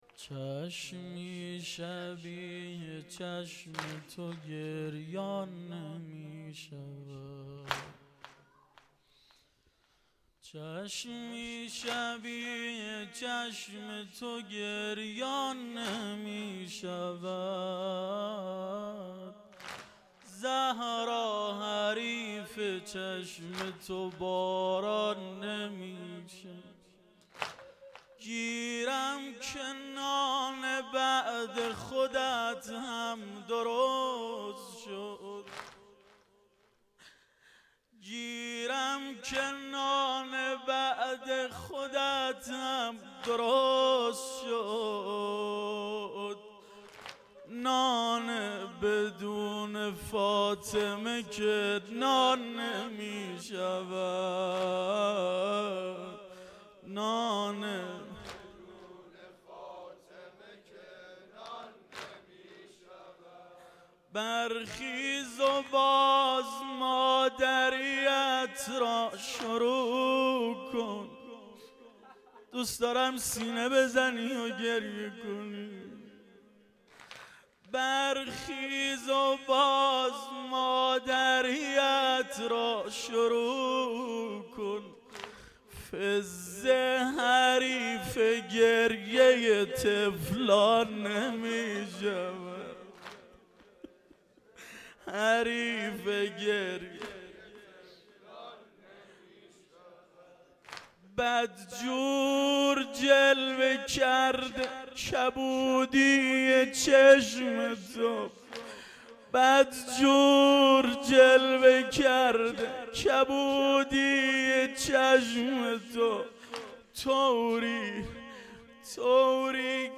واحد2شب چهارم فاطمیه
مداحی